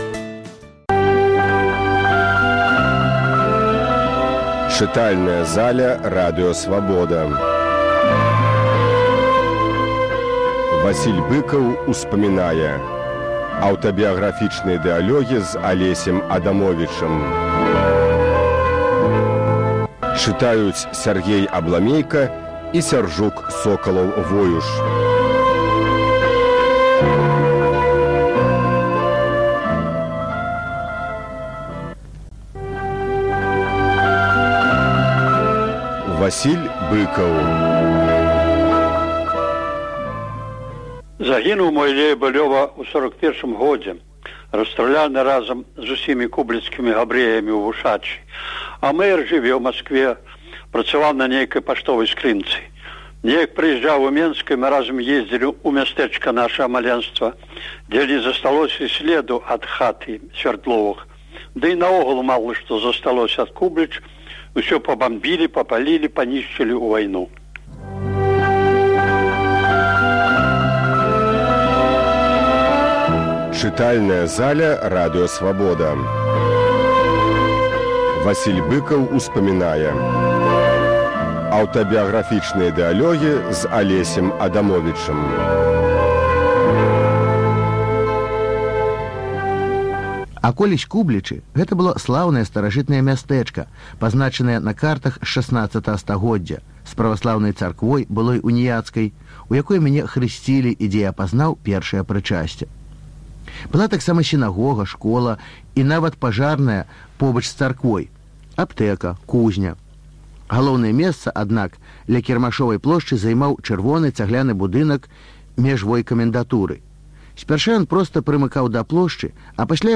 Архіўны запіс перадачы, якая ўпершыню прагучала на хвалях Свабоды ў 2001 годзе